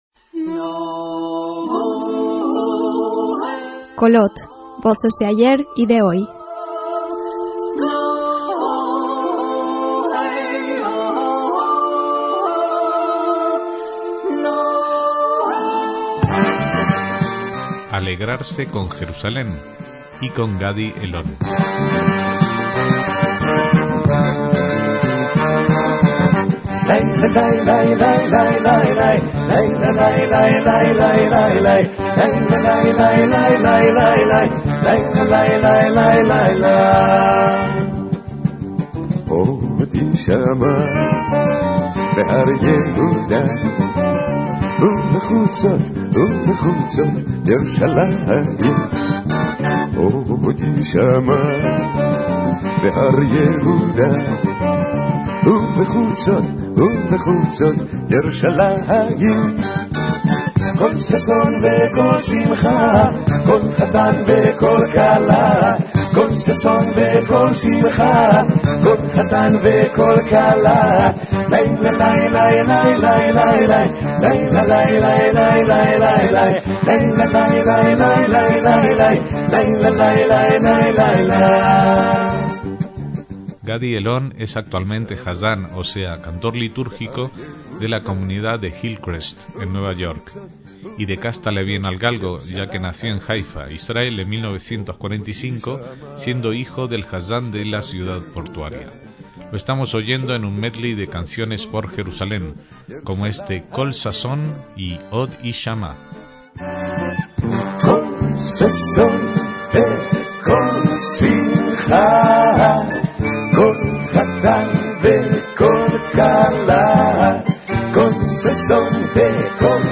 KOLOT: VOCES DE AYER Y DE HOY - En los próximos días celebraremos Yom Yerushalayim, el Día de Jerusalén, que recuerda la fecha hebrea de la reunificación de la capital de Israel durante la Guerra de los Seis Días en 1967.